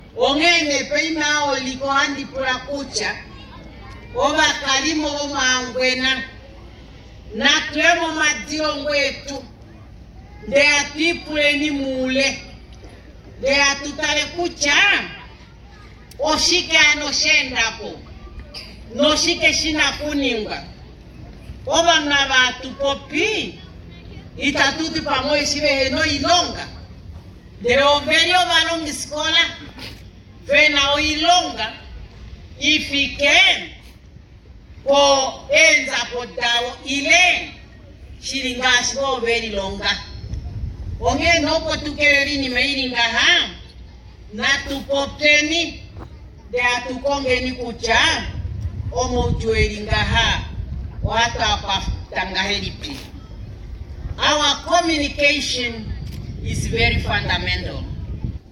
Omupresident okwali ta popi pethimbo ta egulula iipindi mEenhana, konima sho kwa lopotwa omwaalu gwaantu oyendji ya hulitha konima sho yiikutha oomwenyo muule womasiku omashona elela.